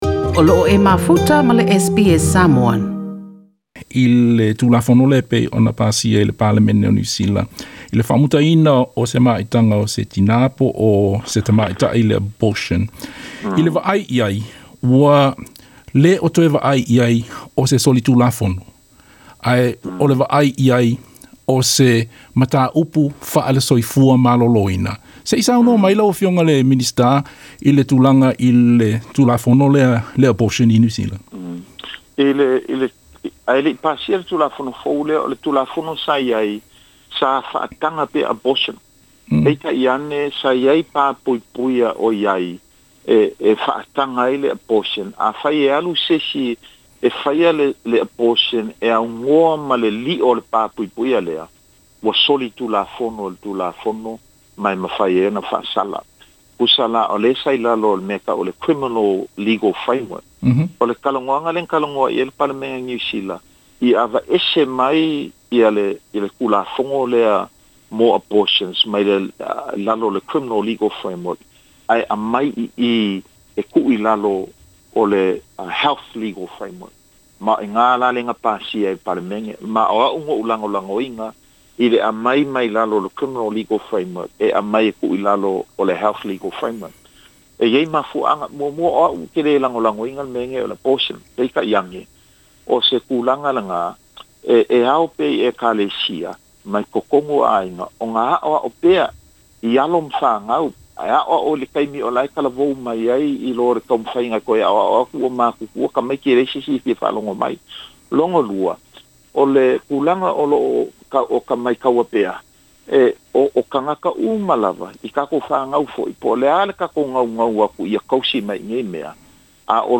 Na saunoa le sui faipule o le itumalo palota o Mangere, le Afioga Aupito William Sio i se talanoaga ma le SBS Samoan, e le faigofie ia te ia le mataupu i le abortion.